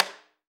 AFRO.TAMB6-S.WAV